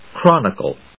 音節chron・i・cle 発音記号・読み方
/krάnɪkl(米国英語), krˈɔnɪkl(英国英語)/